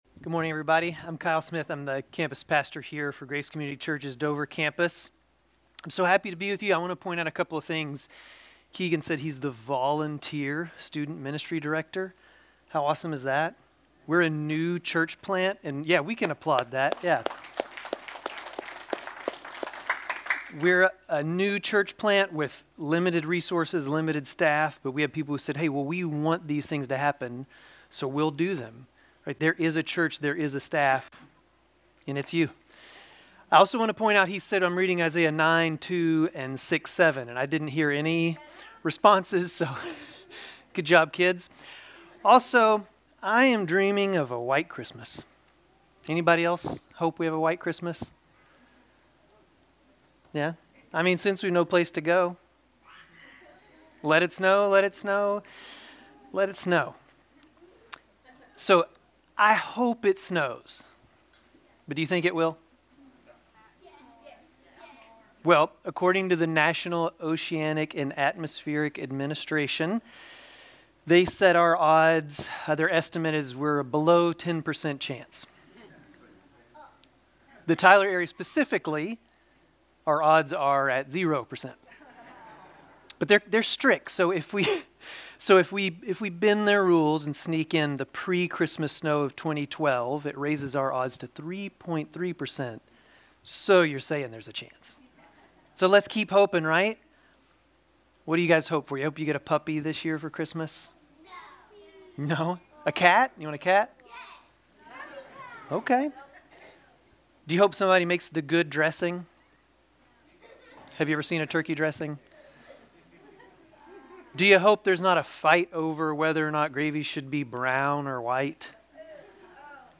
Grace Community Church Dover Campus Sermons 11_30 Dover Campus Dec 01 2025 | 00:25:51 1x 00:00 / 00:25:51 Subscribe Share RSS Feed Share Link Embed